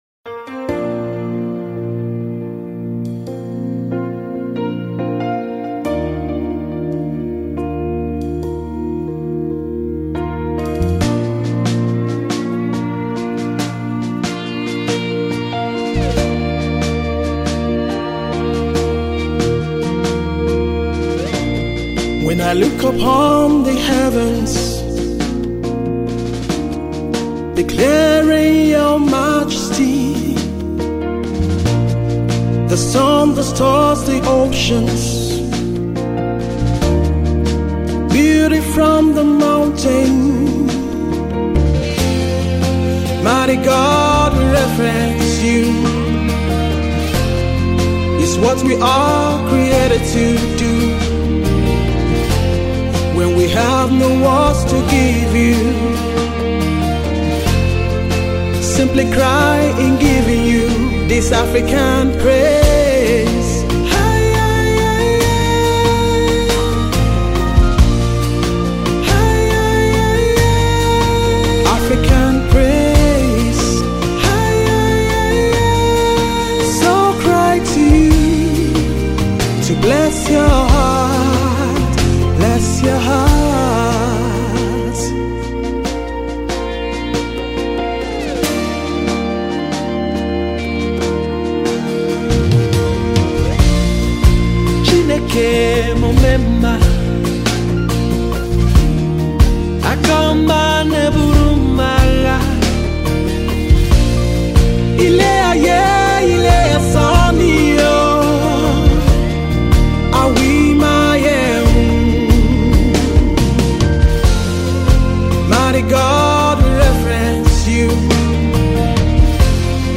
in Gospel Music
heartfelt worship song
energetic vocalist
female gospel jazz singer